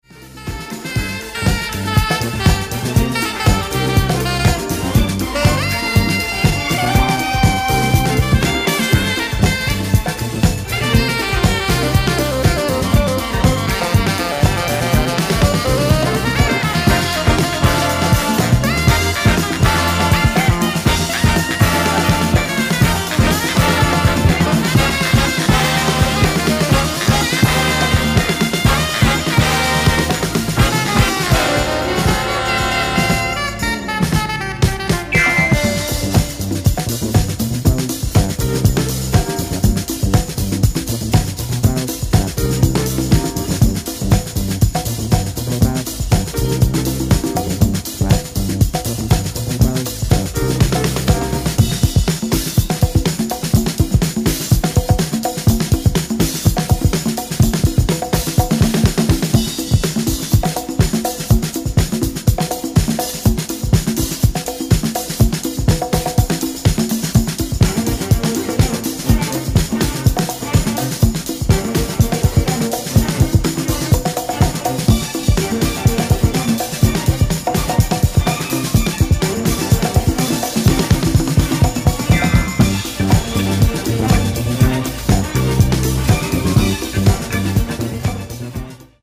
タイトで黒いベース・ラインとギターのカッティングとの絡みが最高な大人気曲